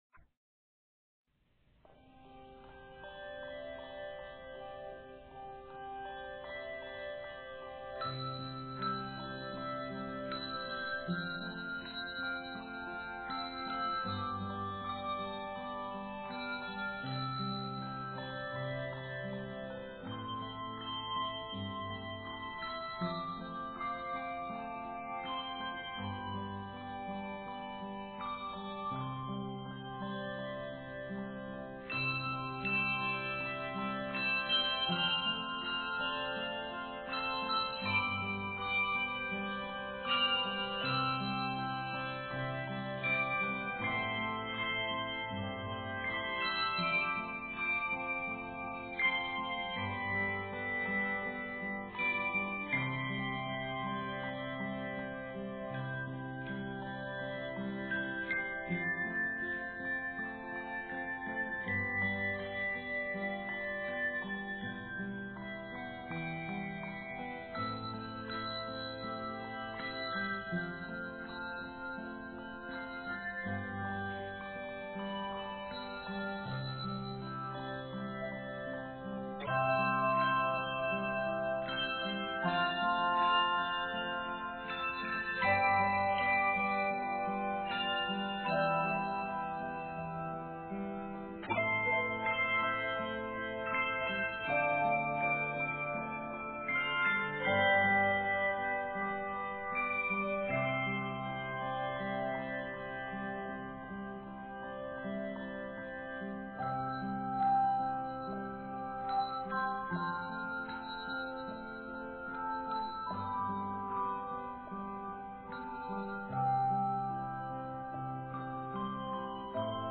A traditional Latin American melody